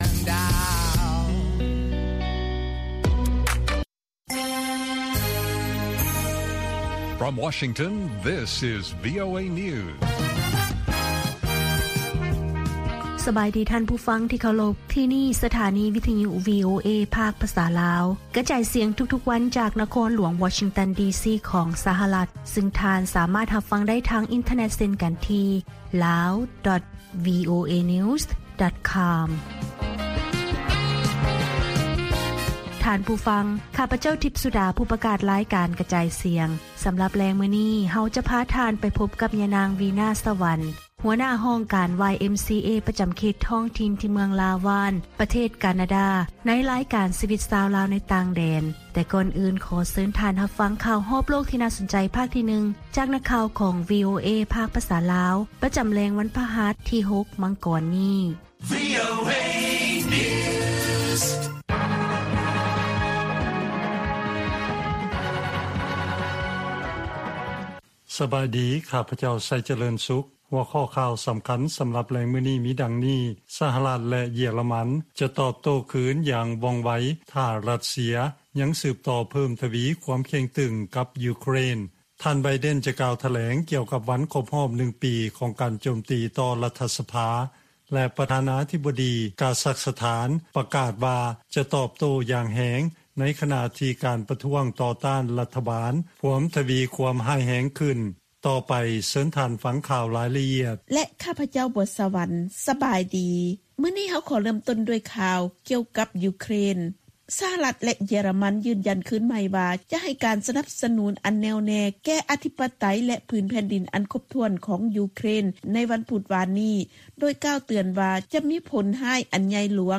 ລາຍການກະຈາຍສຽງຂອງວີໂອເອ ລາວ: ສະຫະລັດ ແລະເຢຍຣະມັນຈະ 'ຕອບໂຕ້ຄືນຢ່າງວ່ອງໄວ' ຖ້າຣັດເຊຍ ຍັງສືບຕໍ່ເພີ້ມທະວີຄວາມເຄັ່ງຕຶງກັບຢູເຄຣນ